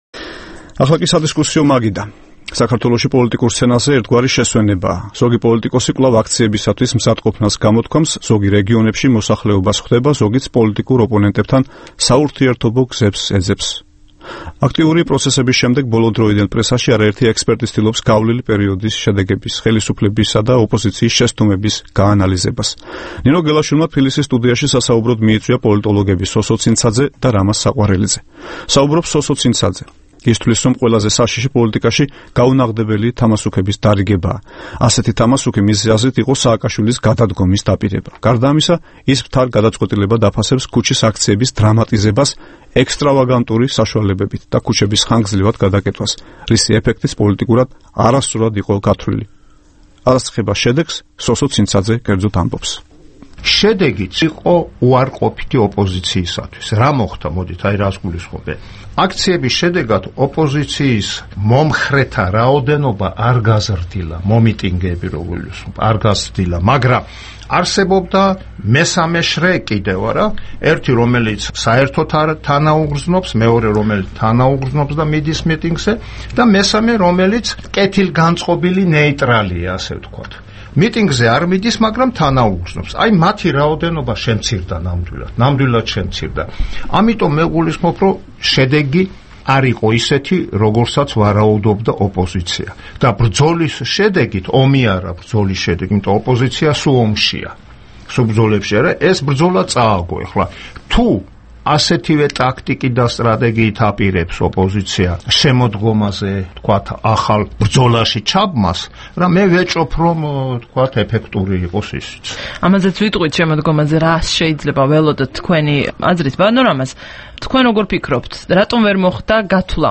თბილისის სტუდიაში სასაუბროდ მიიწვია პოლიტოლოგები